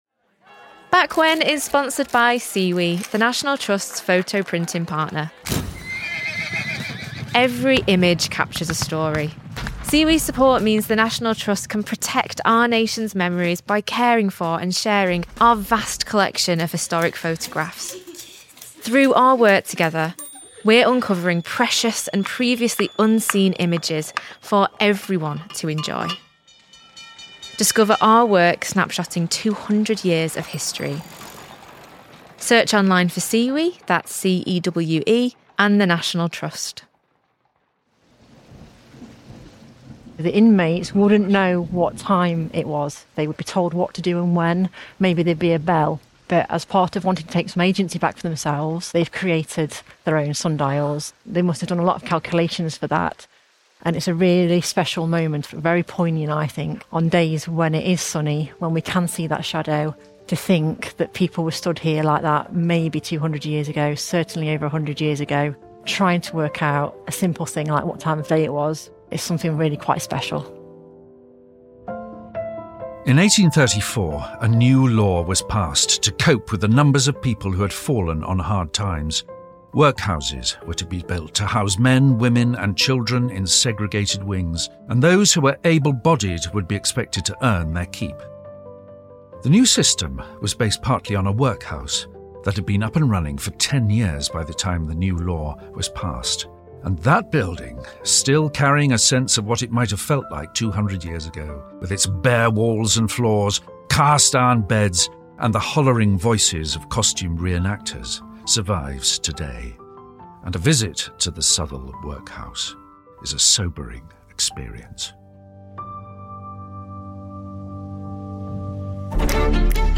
With its bare walls and floors, cast iron beds, and the hollering voices of costumed re-enactors, a visit to the Southwell Workhouse is a sobering experience.